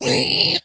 zpighurt1.ogg